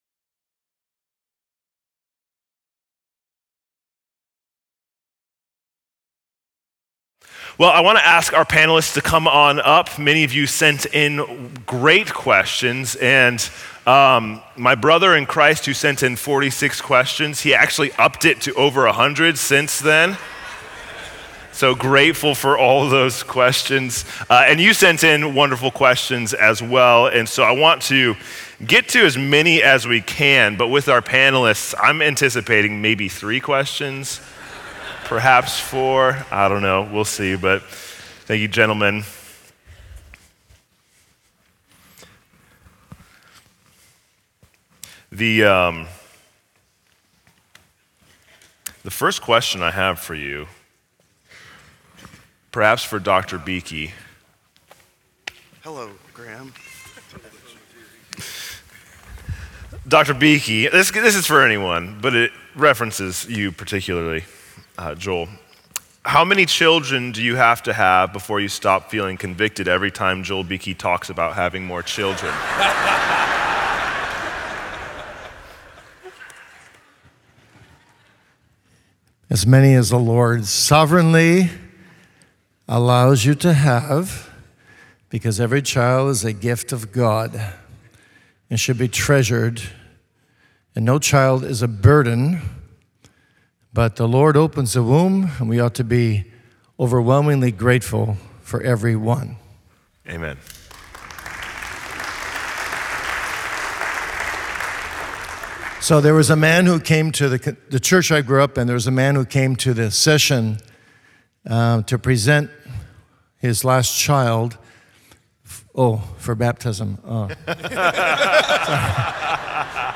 Q&A Panel Discussion: Make Disciples